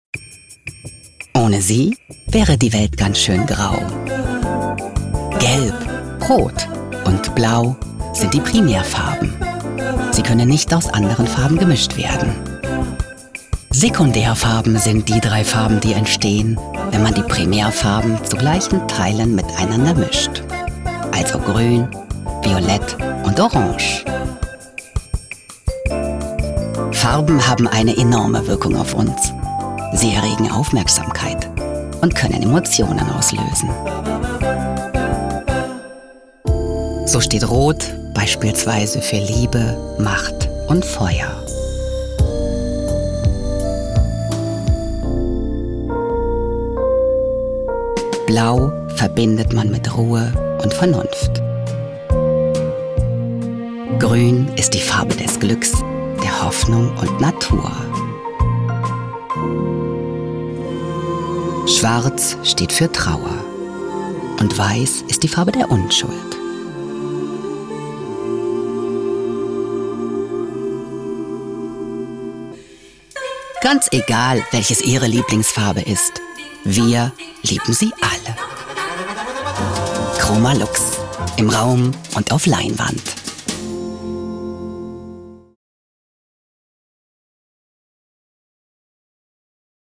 Charakterstimme, erregt Aufmerksamkeit, samtig-tief, einfĂŒhlsam, innovativ, glaubhaft, hohe Wiedererkennung, TV-Werbung, Audioguide, Doku / Off, E-Learning, Feature, Spiele, Funkspot, Imagefilm, Meditation, Nachrichten, ErklĂ€rfilm, Promotion, Sachtext, Trailer, Station-Voice, VoiceOver, Tutorials
Sprechprobe: Industrie (Muttersprache):
Character voice, attracts attention, velvety-deep, sensitive, innovative, credible, high recognition, TV commercial, audio guide, documentary / off-camera, e-learning, feature, games, radio spot, image film, meditation, news, explanatory film, promotion, factual text, trailer, station voice, voiceover, tutorials